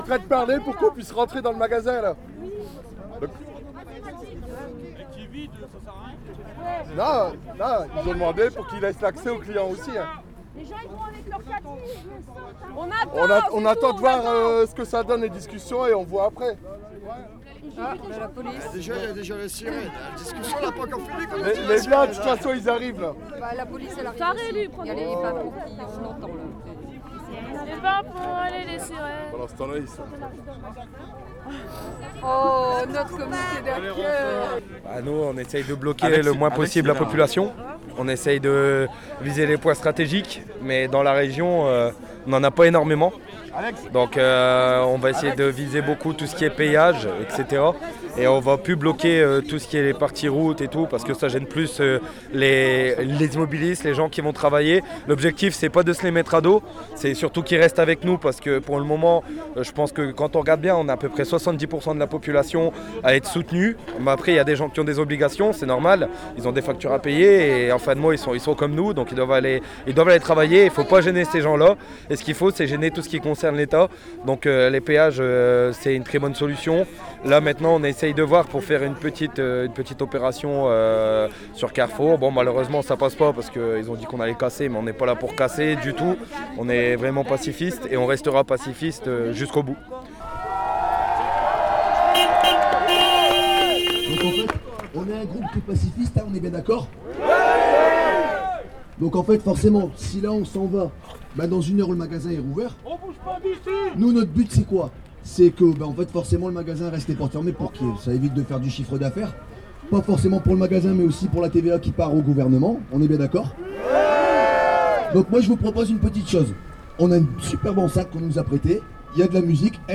Jeudi 22 novembre. Tentative d’action aux caisses de Carrefour Liévin mise en échec.
Témoignage :